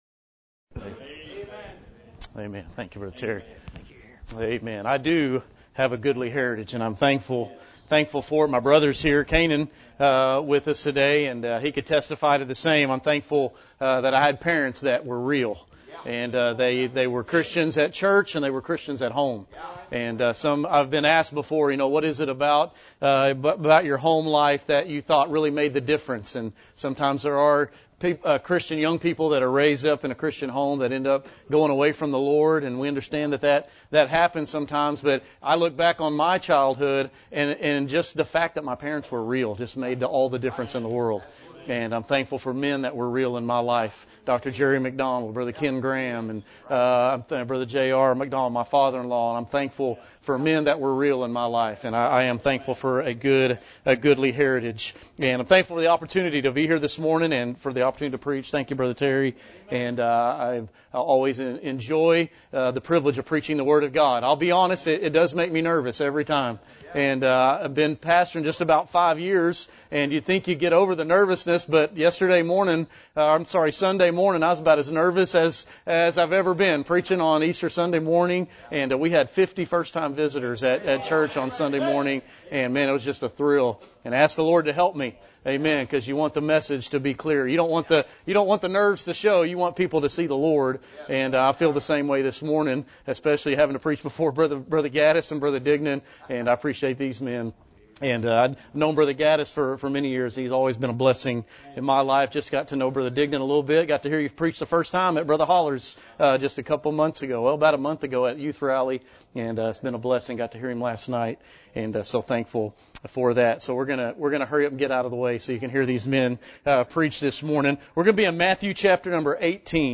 The Essential Need for Radical Christianity – Cornerstone Baptist Church | McAlester, OK